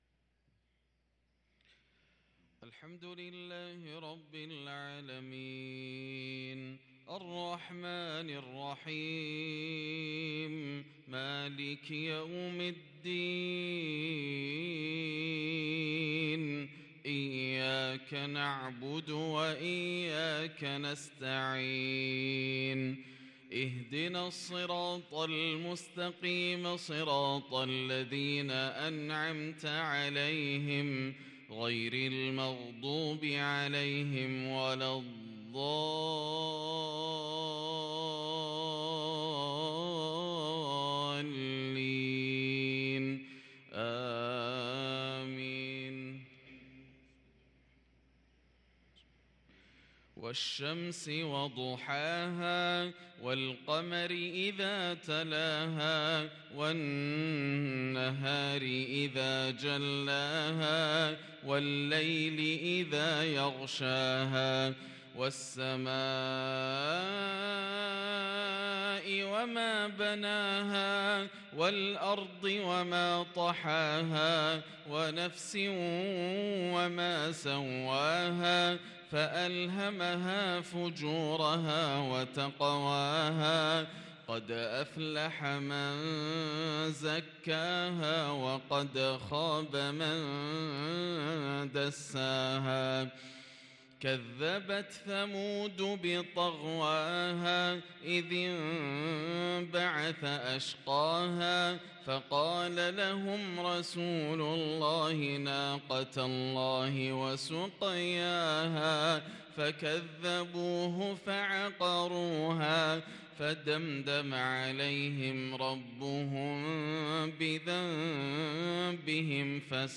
صلاة المغرب للقارئ ياسر الدوسري 15 صفر 1444 هـ
تِلَاوَات الْحَرَمَيْن .